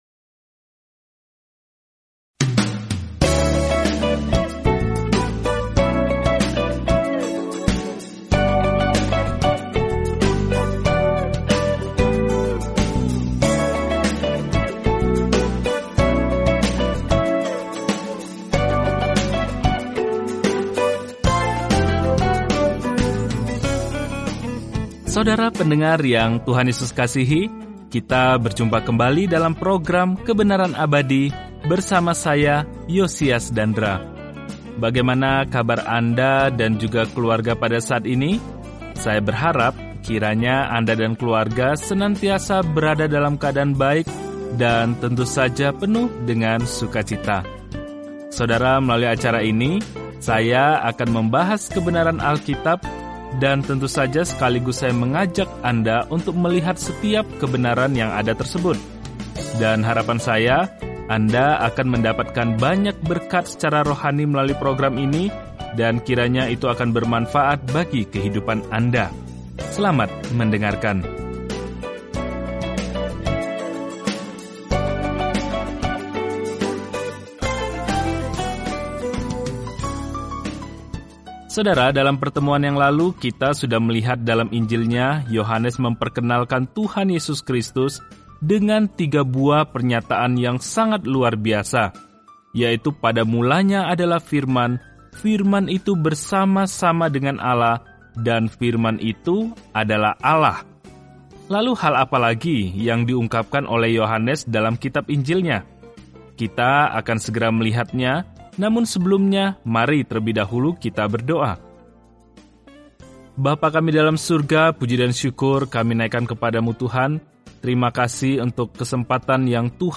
Firman Tuhan, Alkitab Yohanes 1:10-50 Hari 2 Mulai Rencana ini Hari 4 Tentang Rencana ini Kabar baik yang dijelaskan Yohanes unik dibandingkan Injil lainnya dan berfokus pada mengapa kita hendaknya percaya kepada Yesus Kristus dan bagaimana memiliki kehidupan dalam nama ini. Telusuri Yohanes setiap hari sambil mendengarkan pelajaran audio dan membaca ayat-ayat tertentu dari firman Tuhan.